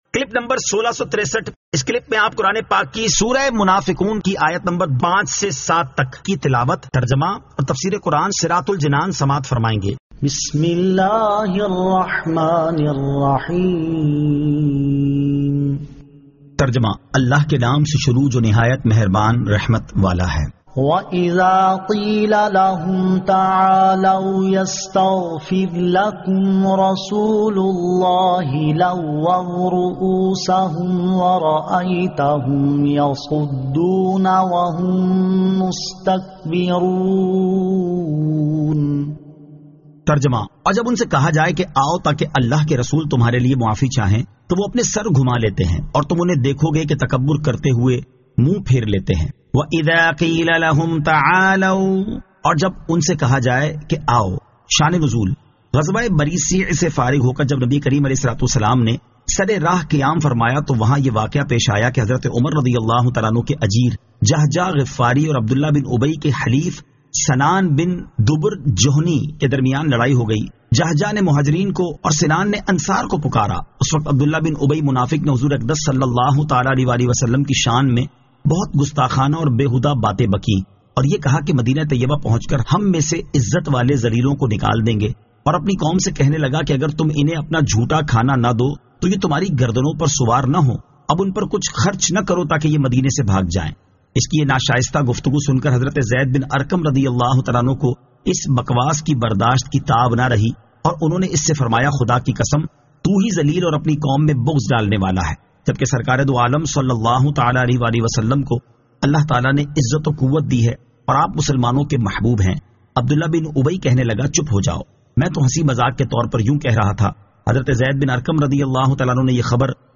Surah Al-Munafiqun 05 To 07 Tilawat , Tarjama , Tafseer
2024 MP3 MP4 MP4 Share سُوَّرۃُ المُنَافَقُوٗن آیت 05 تا 07 تلاوت ، ترجمہ ، تفسیر ۔